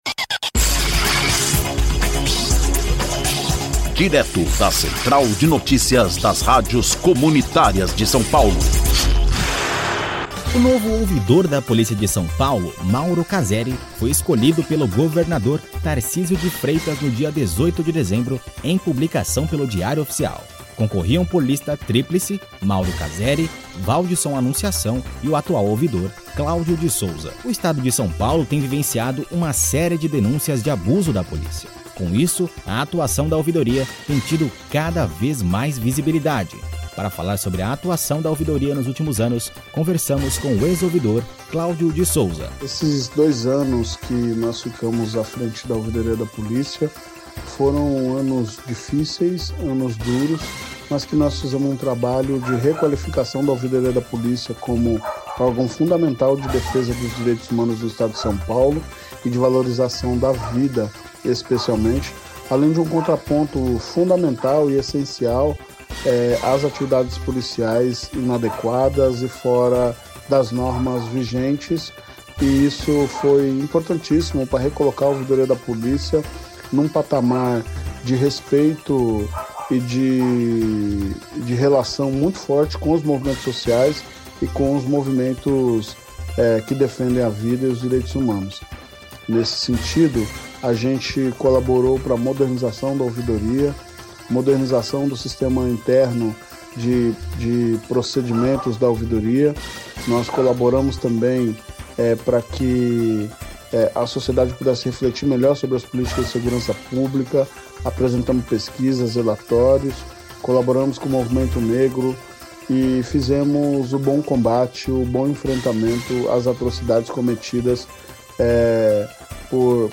Para falar sobre a atuação da Ouvidoria nos últimos anos conversamos com o ex-ouvidor Cláudio de Souza.